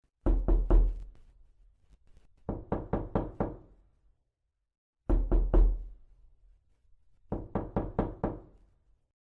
KNOCK KNOCK KNOCK
A light knocking on the door stopped that train of thought, startling him slightly.